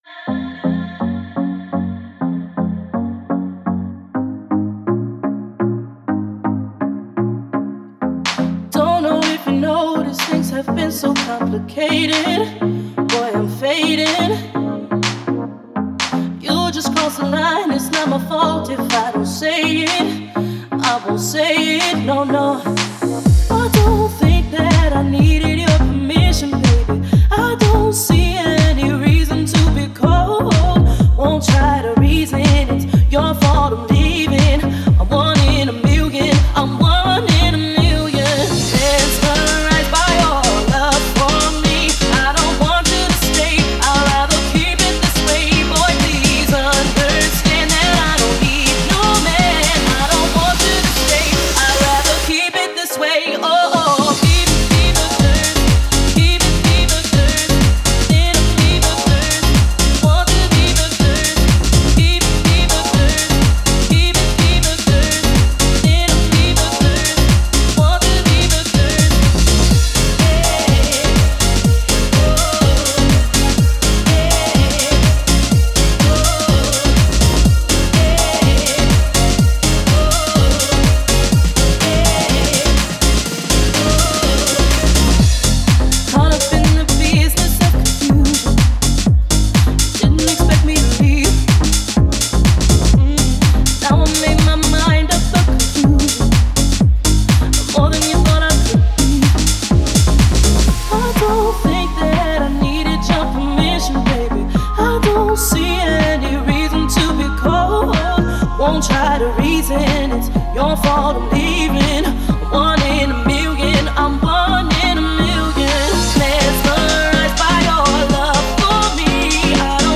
это трек в жанре поп с элементами инди